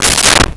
Car Door Close 03
Car Door Close 03.wav